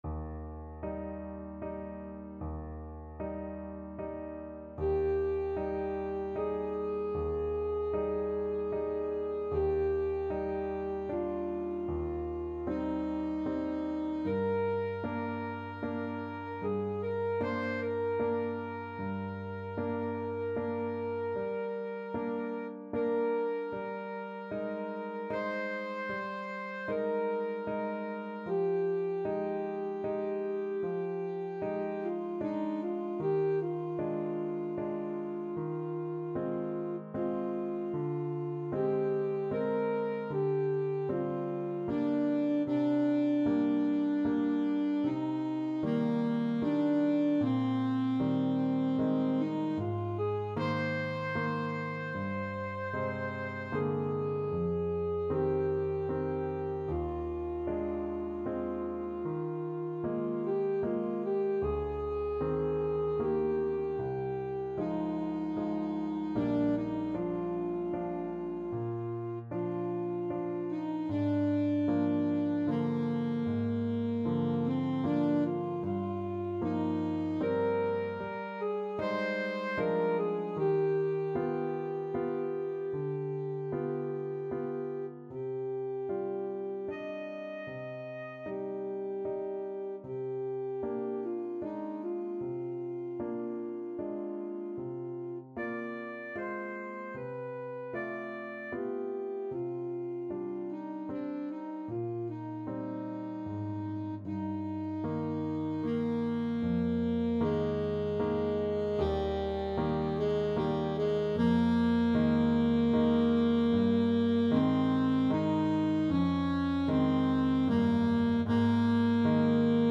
Alto Saxophone
Adagio assai =76
Eb major (Sounding Pitch) C major (Alto Saxophone in Eb) (View more Eb major Music for Saxophone )
Classical (View more Classical Saxophone Music)